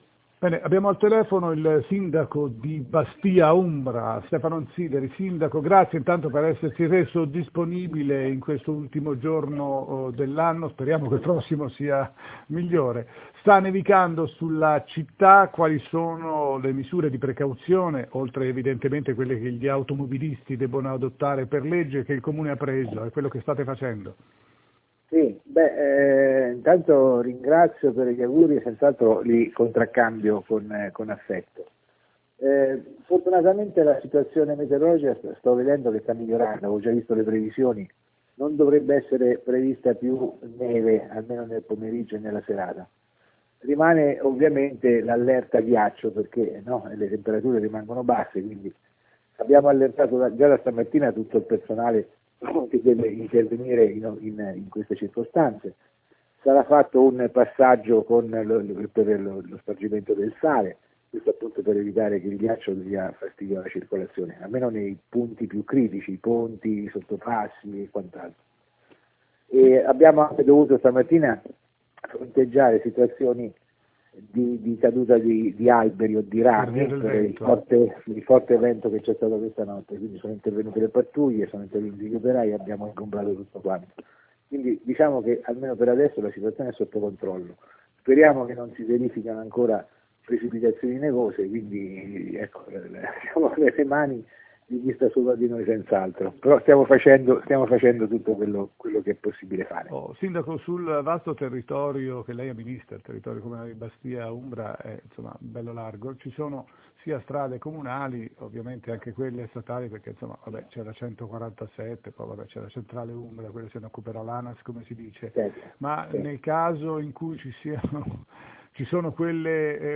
Maltempo, intervista al Sindaco di Bastia Umbra, Stefano Ansideri
Intervista-sindaco-Stefano-Ansideri.mp3